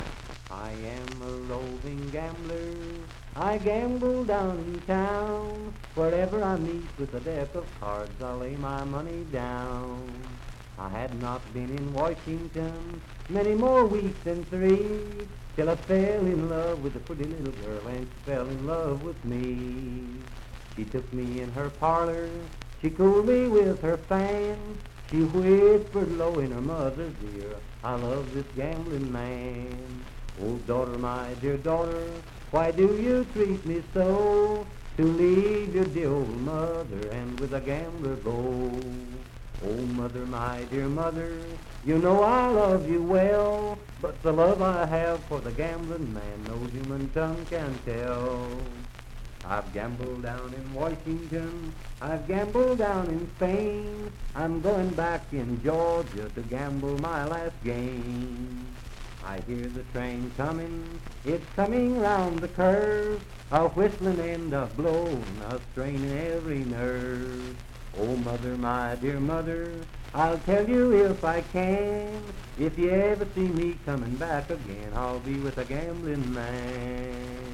Unaccompanied vocal music
Verse-refrain 8(4).
Voice (sung)